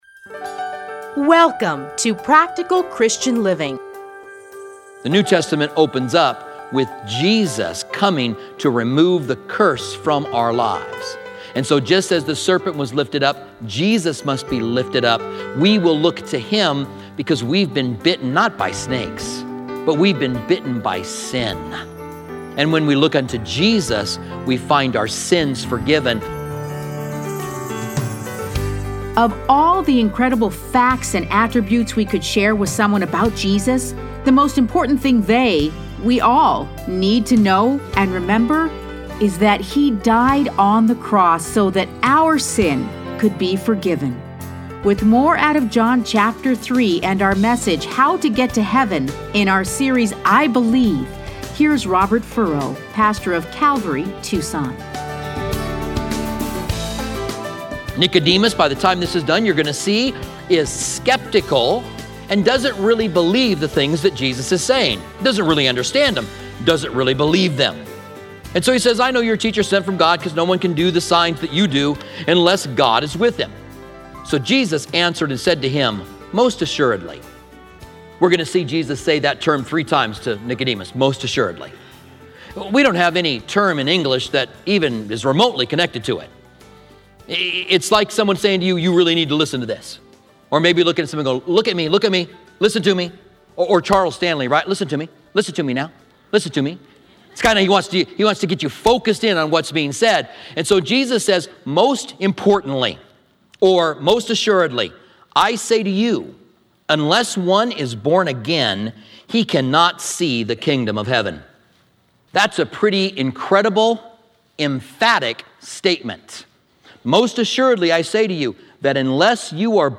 Listen to a teaching from John 3:1-14.